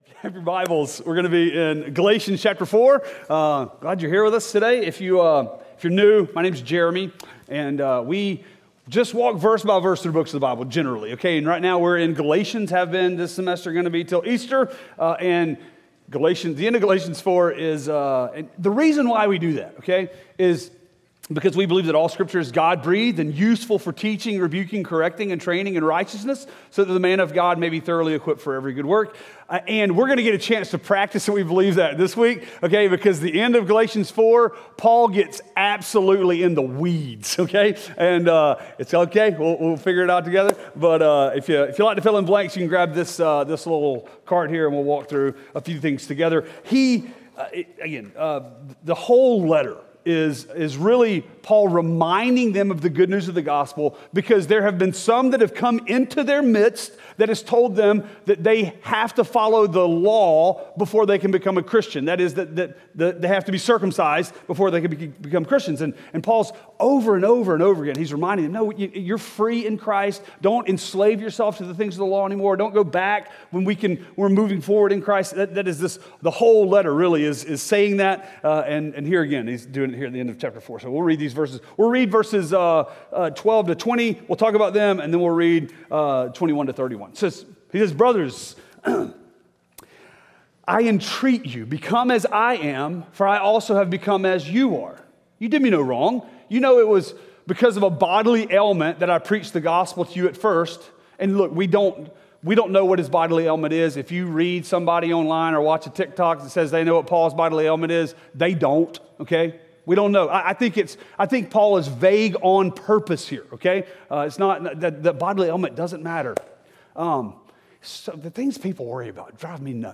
Sermons Galatians Until Christ Is Formed In You! Galatians 4:12-31